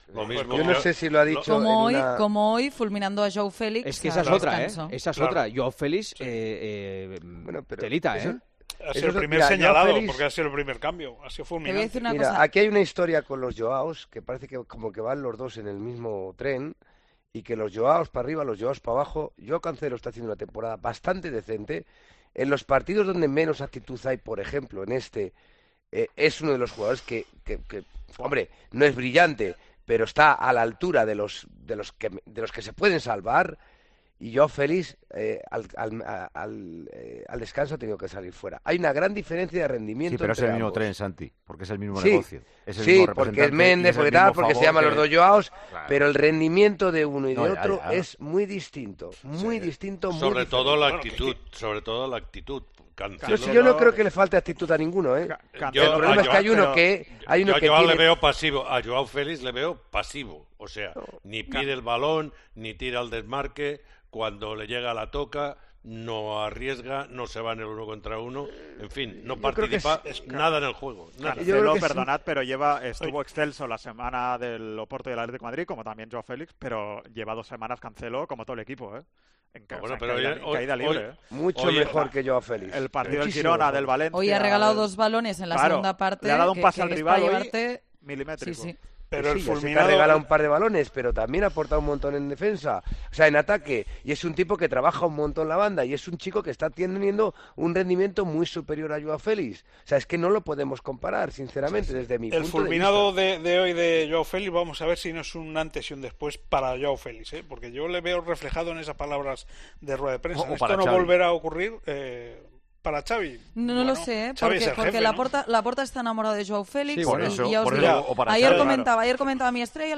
El tertuliano de El Partidazo de COPE valoró la actuación de los dos jugadores portugueses del Barcelona en este primer tramo de temporada.